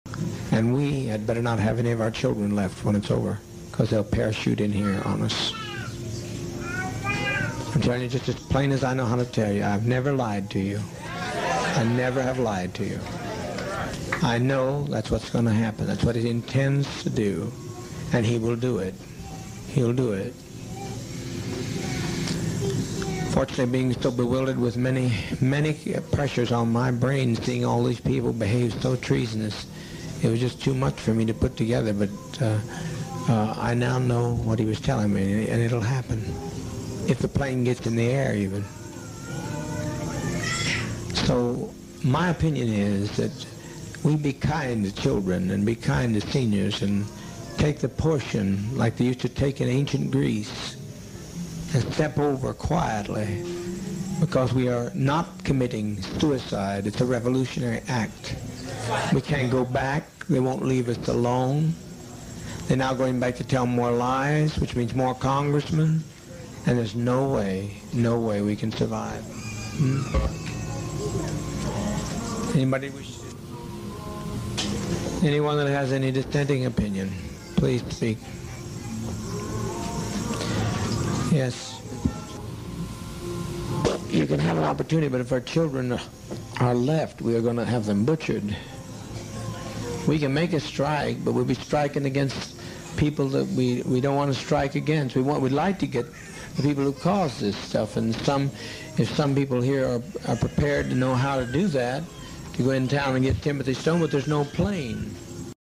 His voice is so creepy sound effects free download